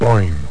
Amiga 8-bit Sampled Voice
boing.mp3